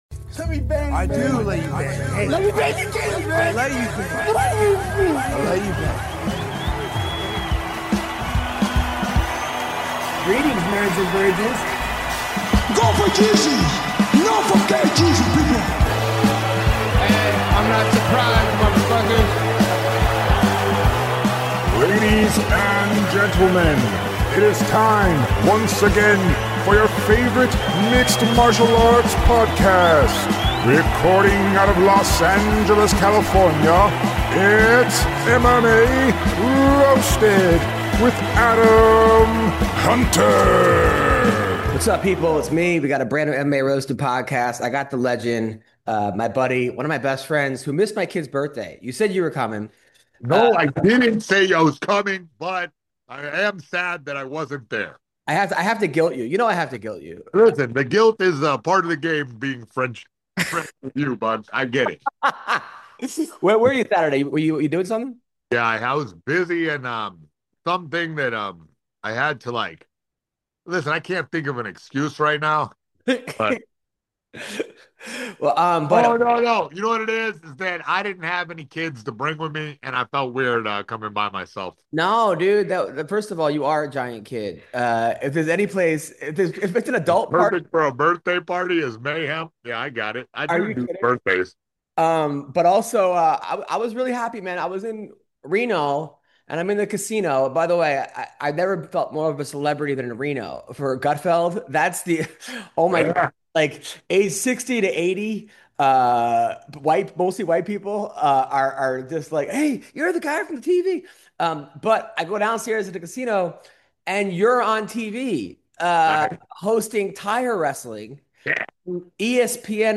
On this episode of The MMA Roasted Podcast, former UFC champion Henry Cejudo calls in to help break down UFC 319!